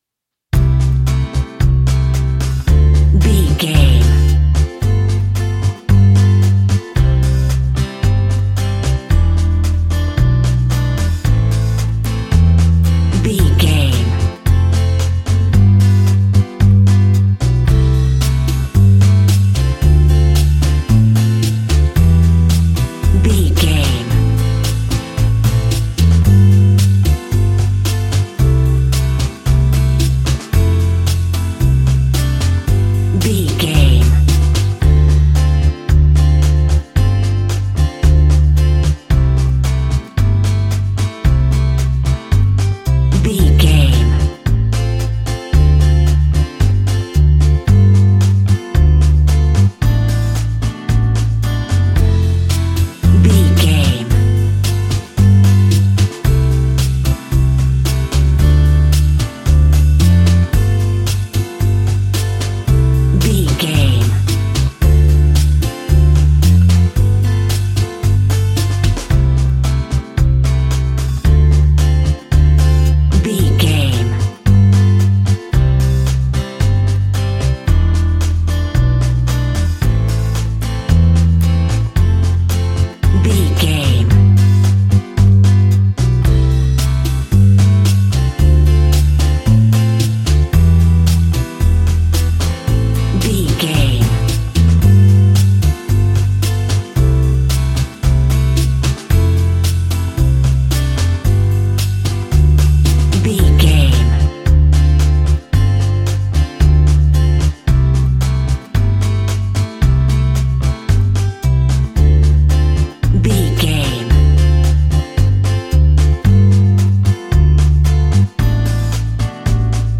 An exotic and colorful piece of Espanic and Latin music.
Uplifting
Ionian/Major
flamenco
maracas
percussion spanish guitar